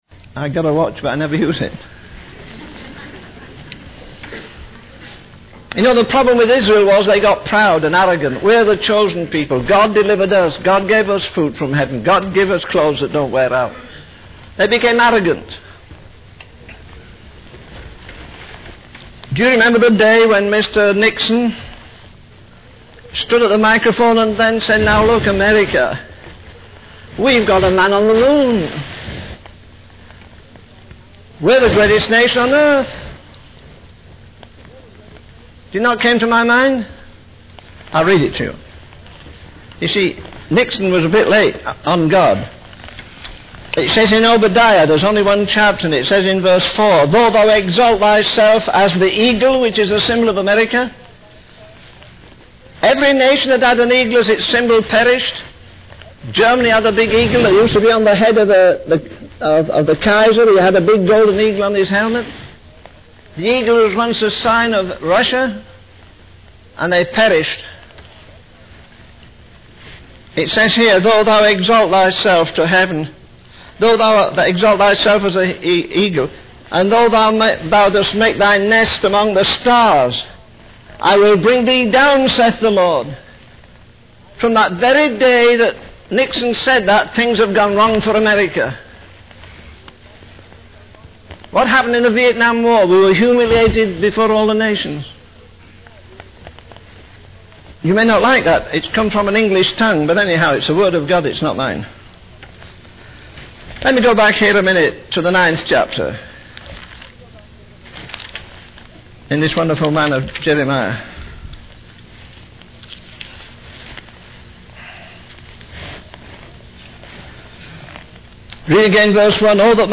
In this sermon, the preacher expresses deep sorrow and concern for the state of the nation. He emphasizes that God's mercy and patience are running out, as they have crucified Jesus and stoned the prophets.